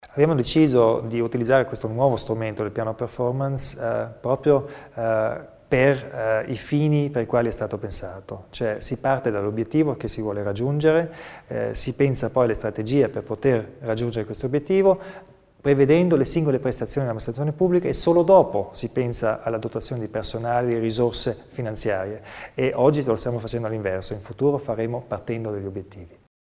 Il Presidente Kompatscher spiega il futuro del settore dell'energia
Il riordino di due grandi settori - l'amministrazione provinciale e l'energia - sono stati tra i temi al centro della "clausura" di due giorni della Giunta, che si è conclusa oggi (9 gennaio) con la conferenza stampa di Presidente e Assessori.